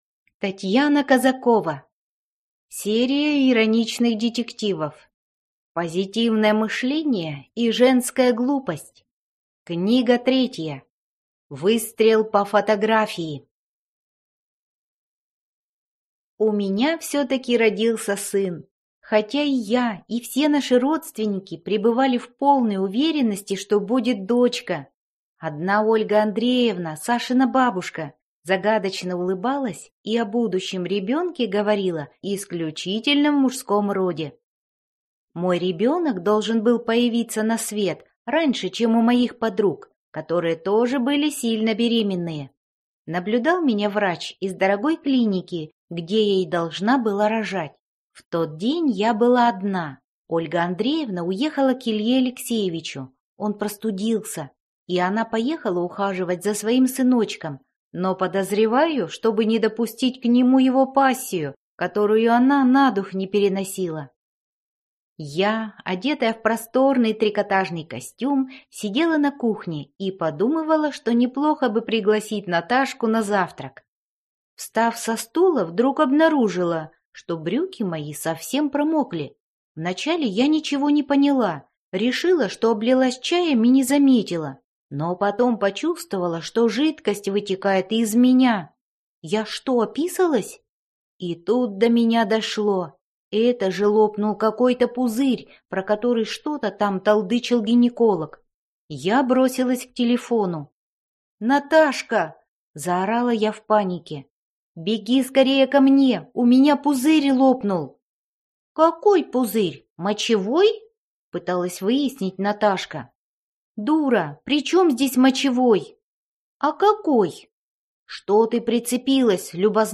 Аудиокнига Выстрел по фотографии | Библиотека аудиокниг
Прослушать и бесплатно скачать фрагмент аудиокниги